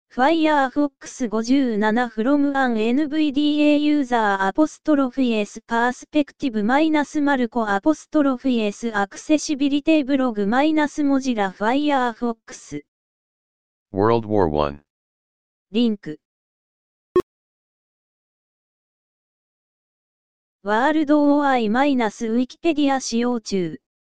記事で述べられていたとおり、「World War I」というリンクを開いてからページの内容が読み上げられるまでには差があることがわかりました。 せっかくですので、NVDAの読み上げ音声で違いをご紹介したいと思います。